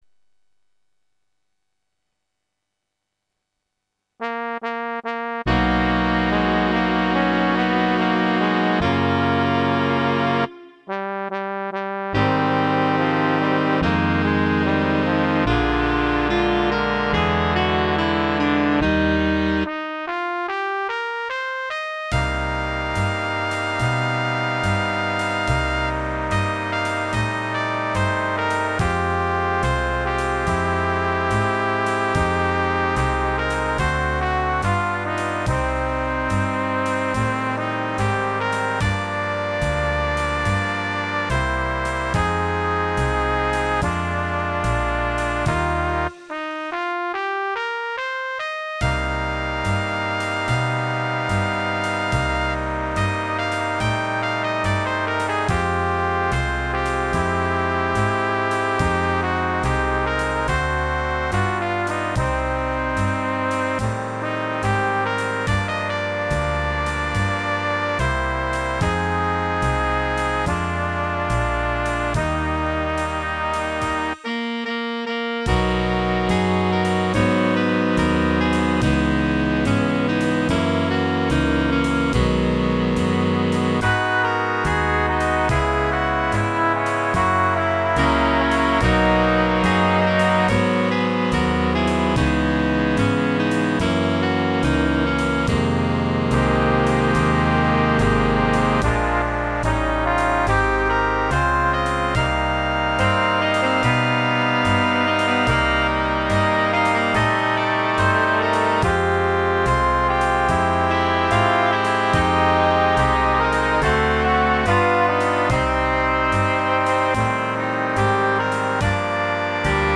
Piano / Guitar / Bass / Drums